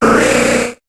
Cri d'Excelangue dans Pokémon HOME.